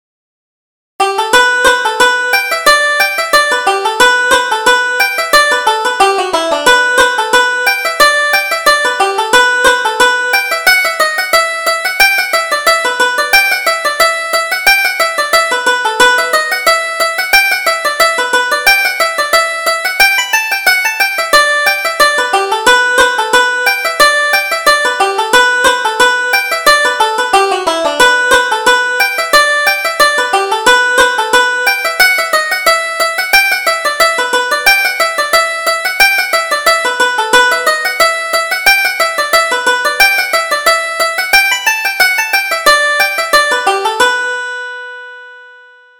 Reel: The Roving Bachelor